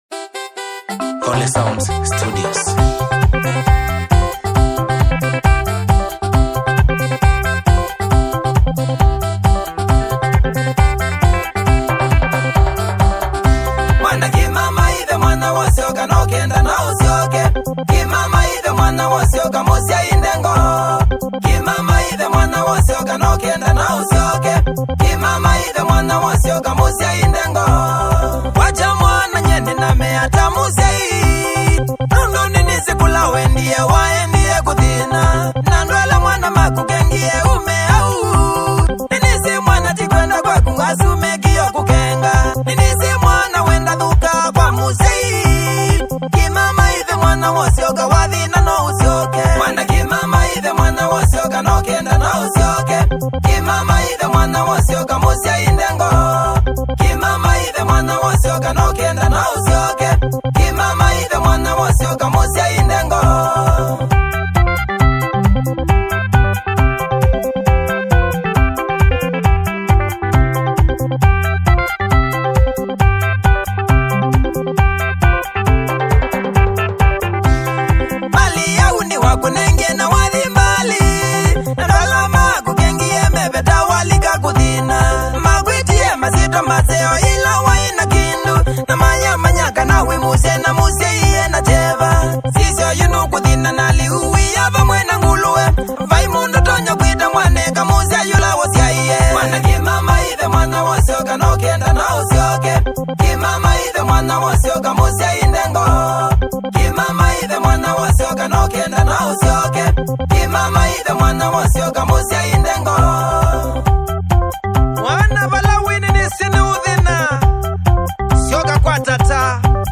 The Kenyan gospel scene
catchy, traditional rhythms